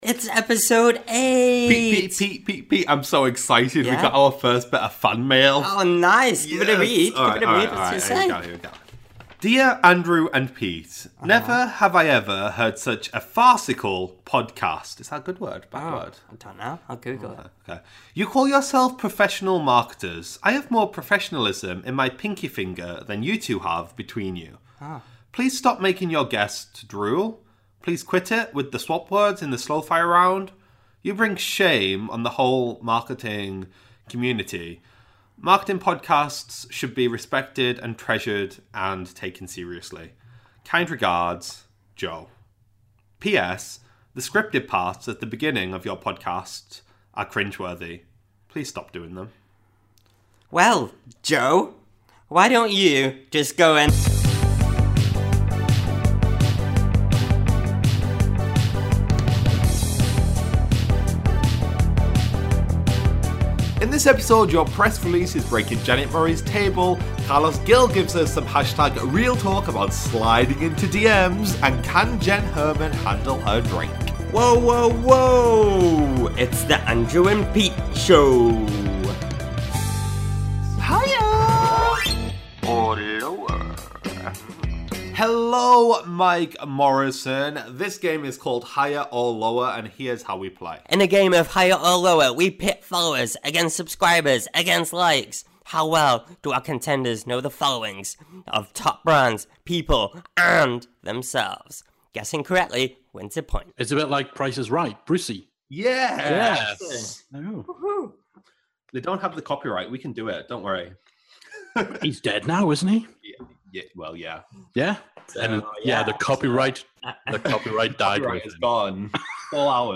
combines all the fun and games of a radio show, whilst interviewing some of the most inspiring and knowledgeable marketeers and entrepreneurs on the planet!